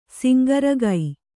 ♪ singaragai